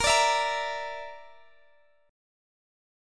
Listen to BbMb5 strummed